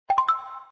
feedback_correct.mp3